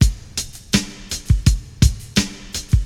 • 83 Bpm Drum Beat G Key.wav
Free drum loop - kick tuned to the G note. Loudest frequency: 1456Hz
83-bpm-drum-beat-g-key-0rU.wav